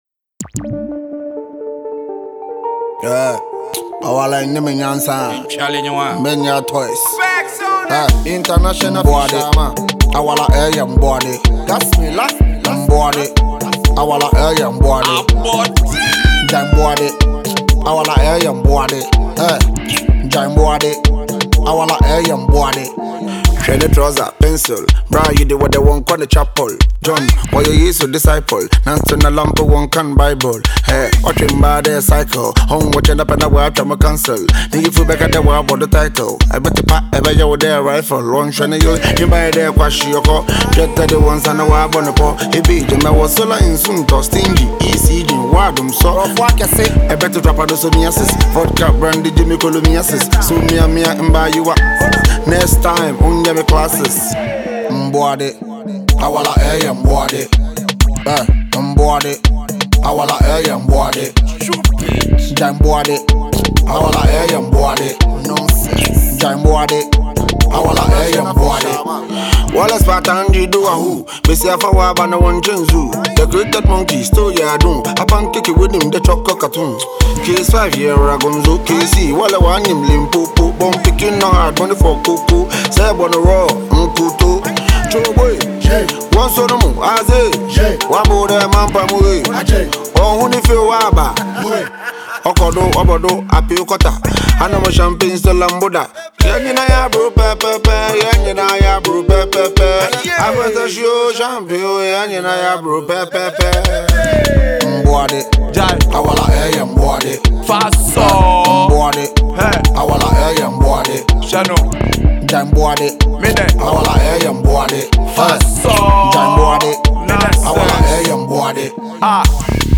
the Ga singer